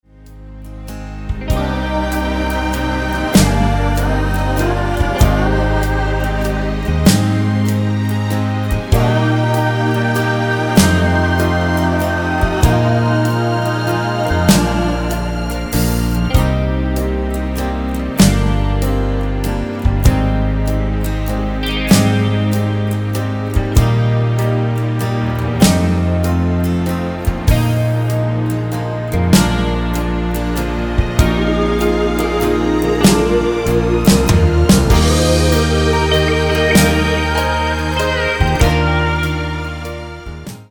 Tonart:Eb mit Chor
Die besten Playbacks Instrumentals und Karaoke Versionen .